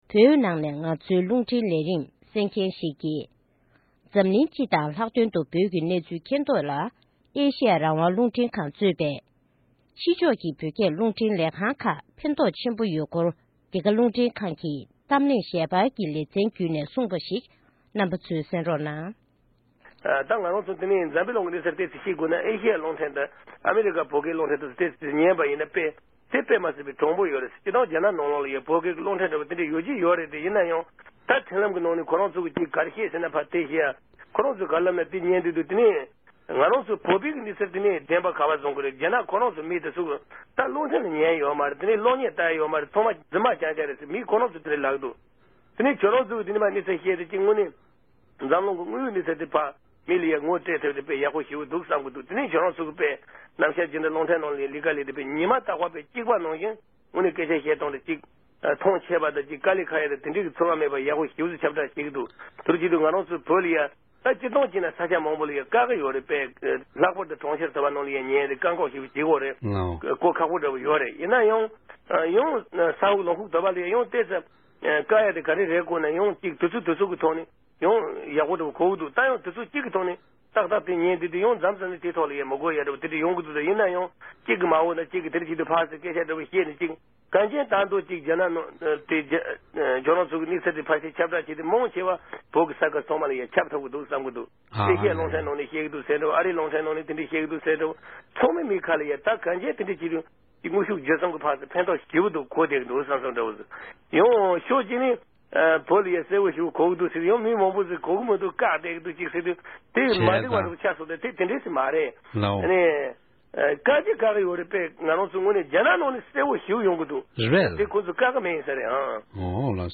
t0125-caller-from-tibet.mp3